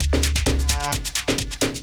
Percasynth-44S.wav